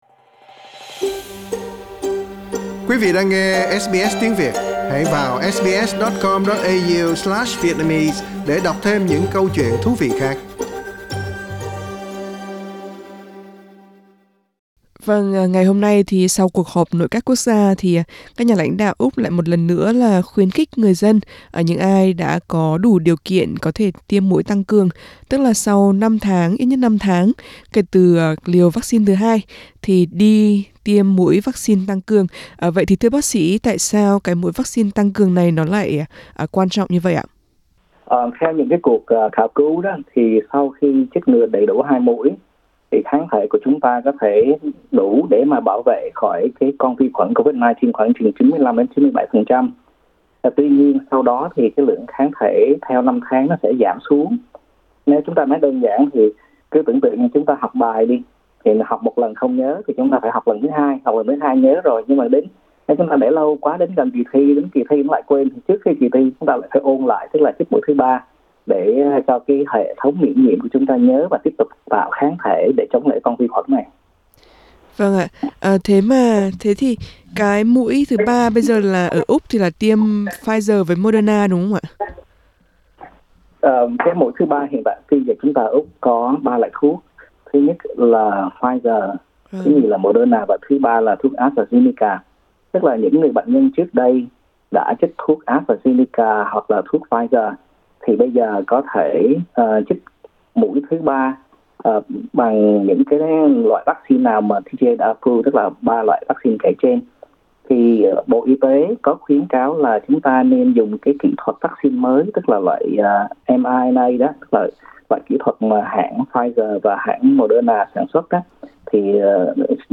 Xin mời quý vị bấm vào hình để nghe toàn bộ cuộc trò chuyện.